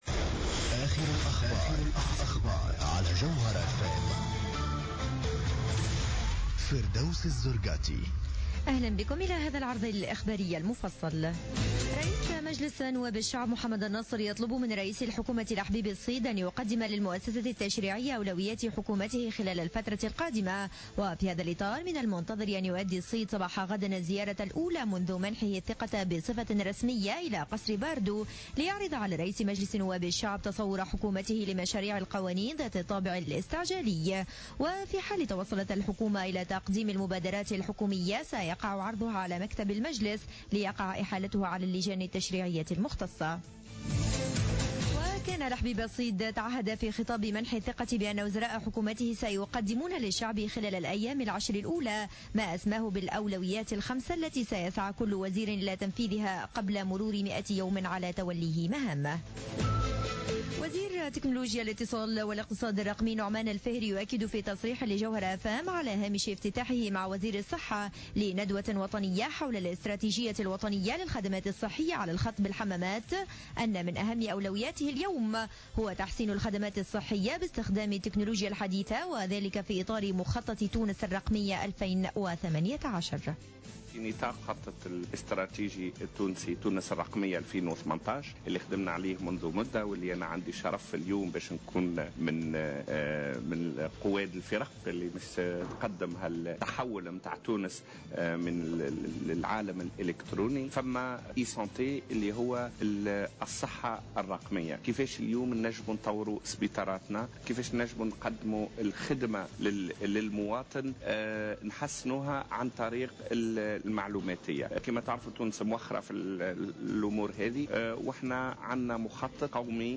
نشرة اخبار السابعة مساء ليوم الأحد 15 فيفري 2015